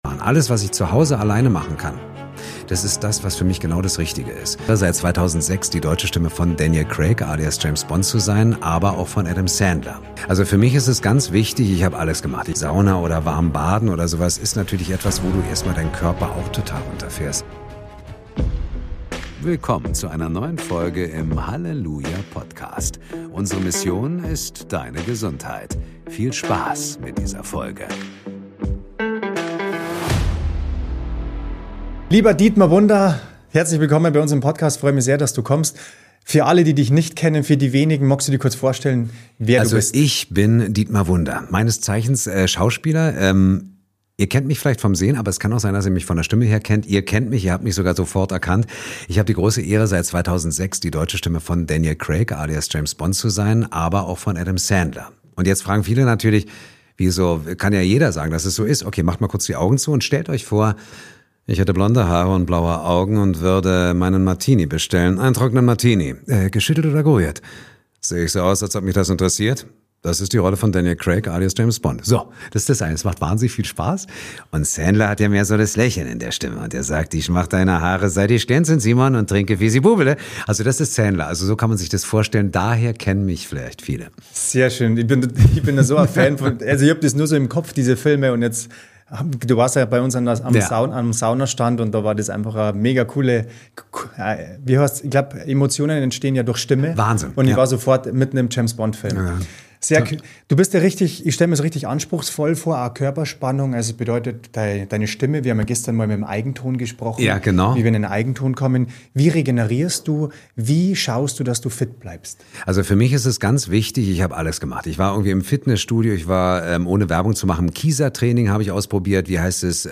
Dietmar Wunder, die deutsche Stimme von James Bond, spricht über